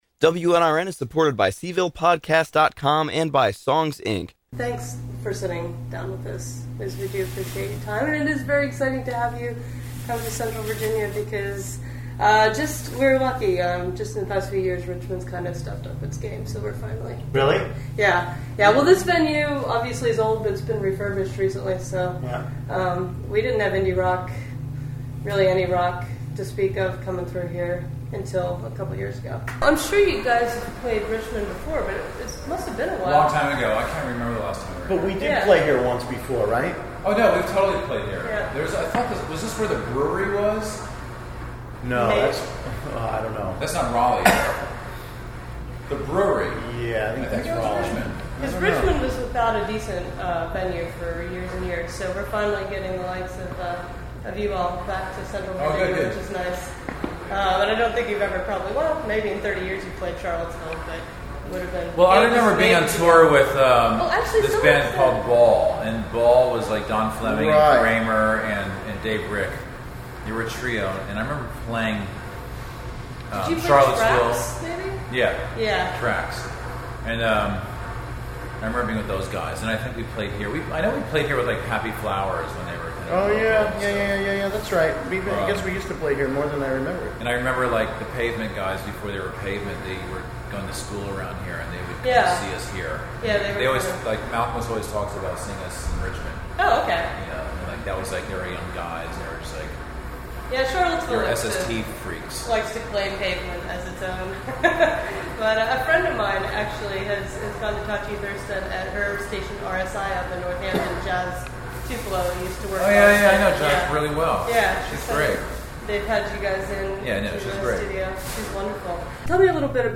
We were very happy to sit down with Thurston Moore and Lee Ronaldo from one of the founding bands of indie rock, Sonic Youth.